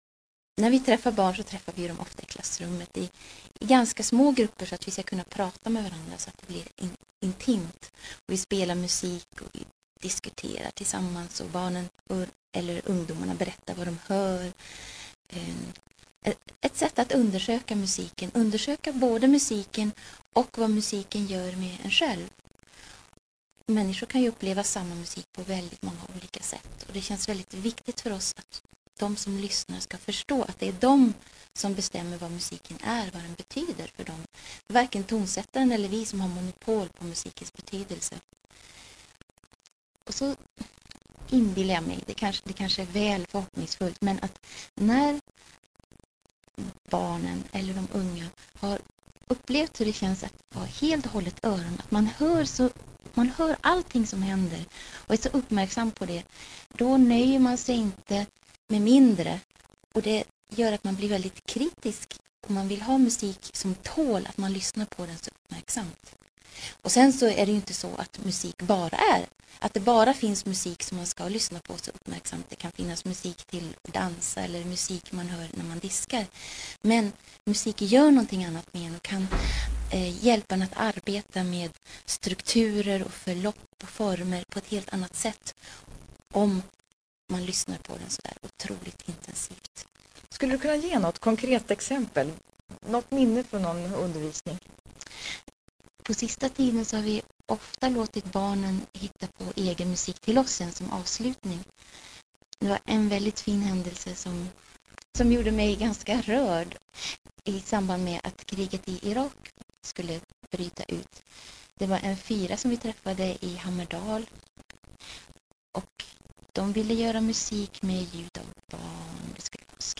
intervju.mp3